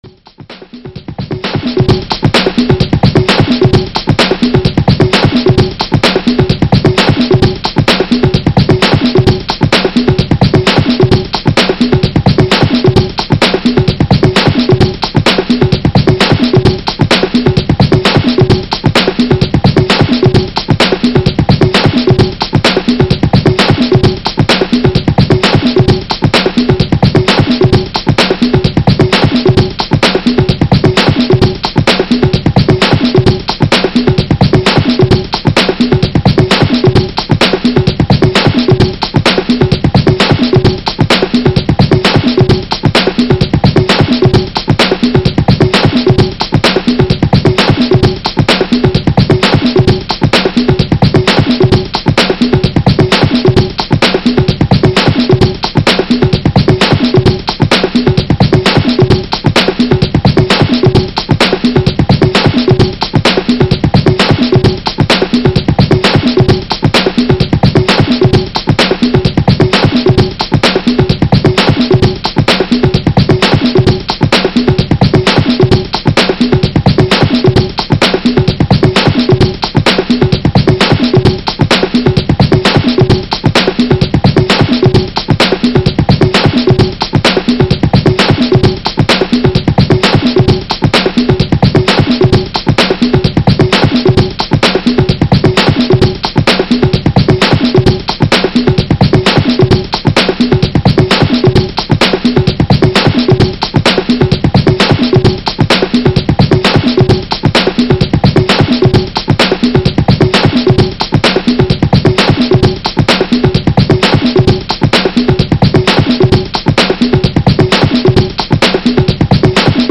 Ce dossier a pour but de réunir des patterns de batterie programmés à divers tempo, afin de pouvoir pratiquer selon ses moyens techniques et ses envies.
Big Beat 3
BigBeat3.mp3